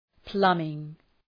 Προφορά
{‘plʌmıŋ}